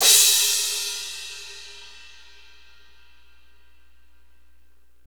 Index of /90_sSampleCDs/Northstar - Drumscapes Roland/CYM_Cymbals 2/CYM_R&B Cymbalsx
CYM R B CR02.wav